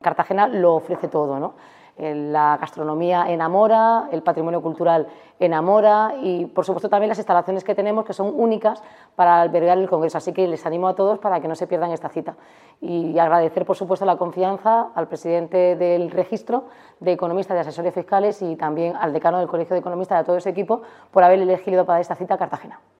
Enlace a Declaraciones de Noelia Arroyo sobre Encuentro Nacional de Economistas Asesores Fiscales